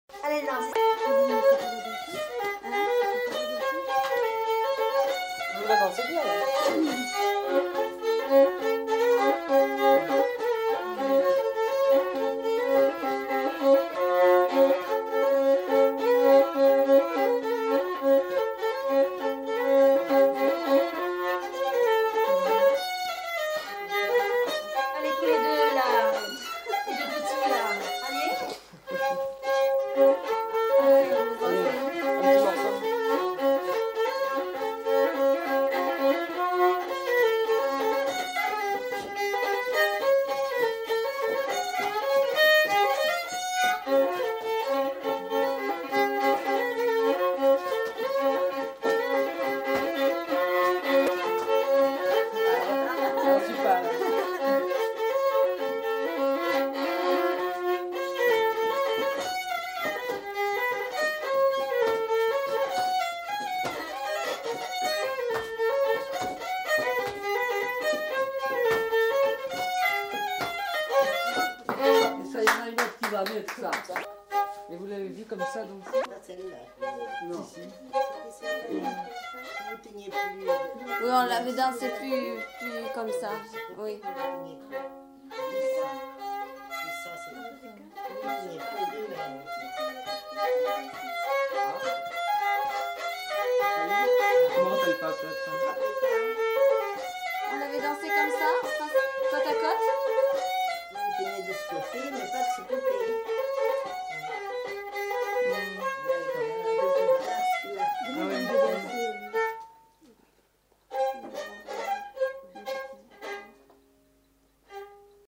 Aire culturelle : Limousin
Genre : morceau instrumental
Instrument de musique : violon
Danse : mazurka